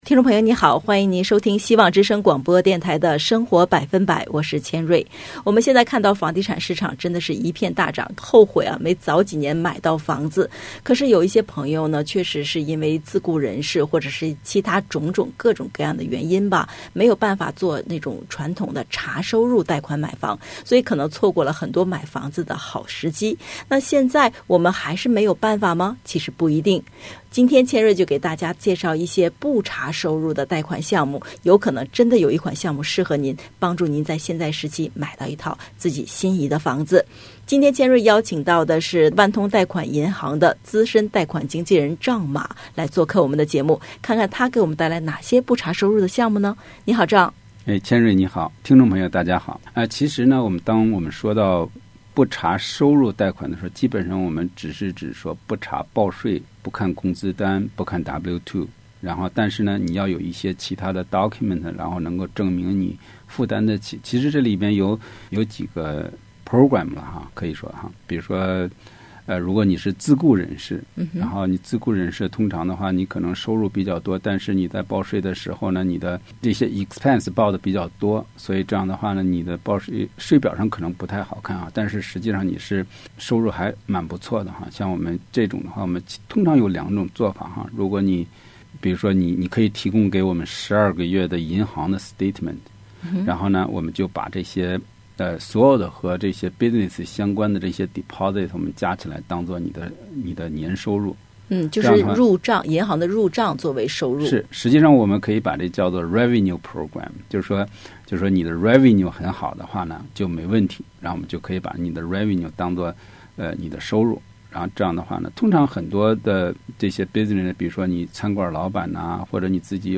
【希望之聲2022年3月17日】（主持人